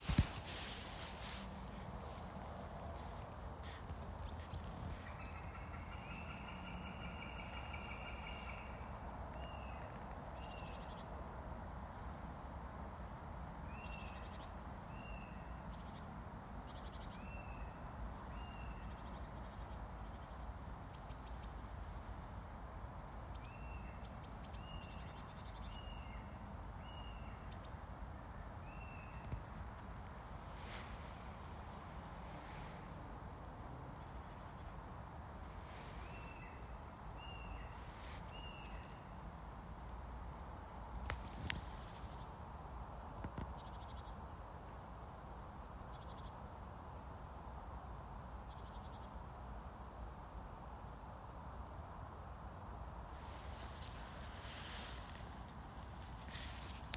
Birds -> Birds of prey ->
Northern Goshawk, Accipiter gentilis
StatusVoice, calls heard